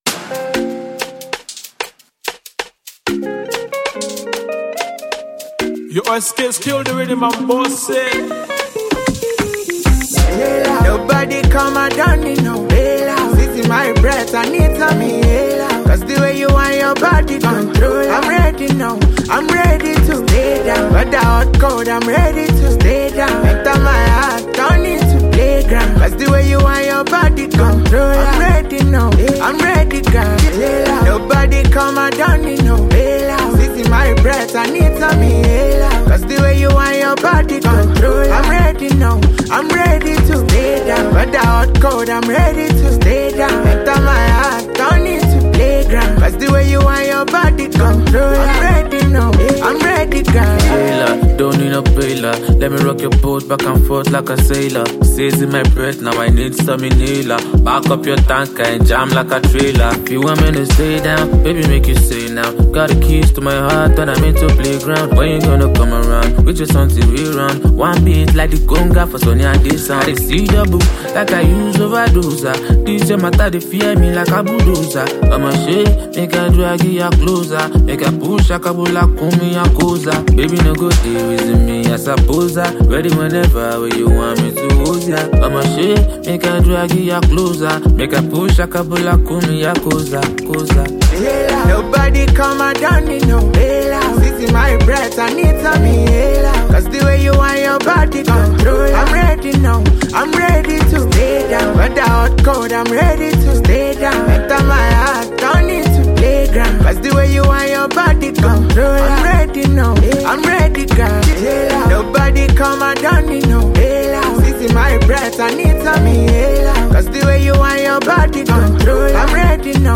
All the song are made of pop sounds .